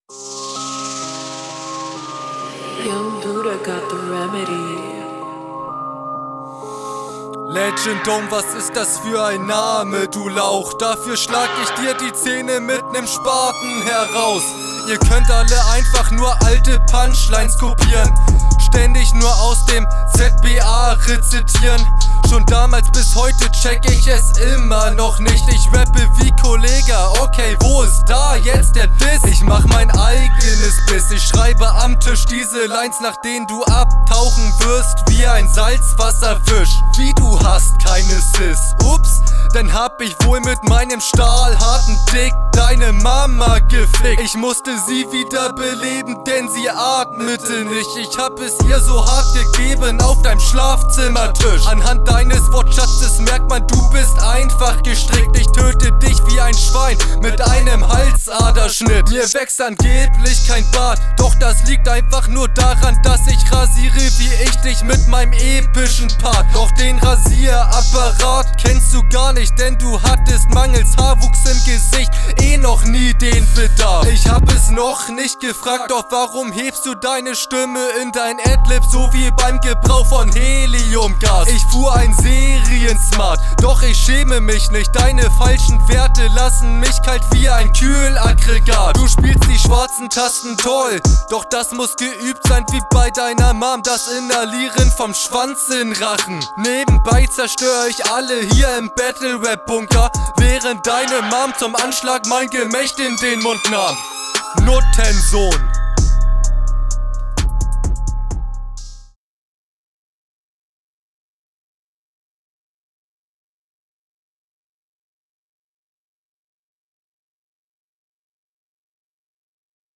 Flow: Einsatz cool. Finde du bringst abwechslung rein und es ist insgesamt rund.